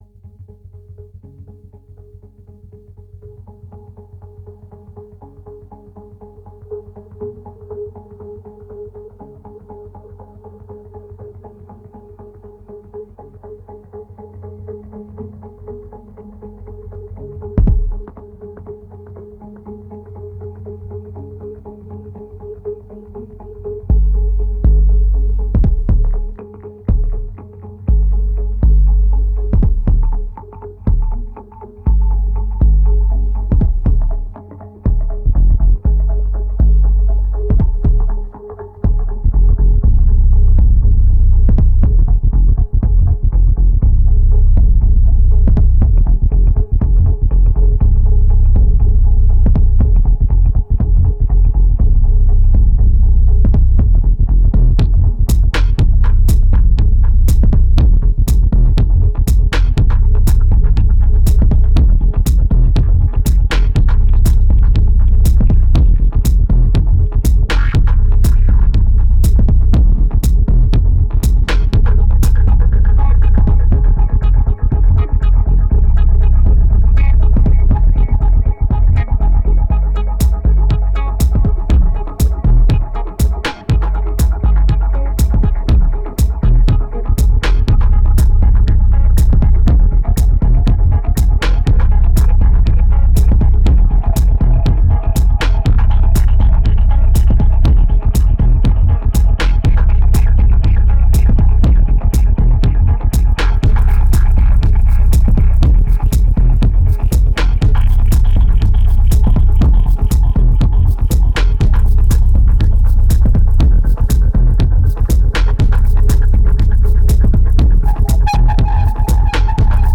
1875📈 - -7%🤔 - 121BPM🔊 - 2010-09-07📅 - -151🌟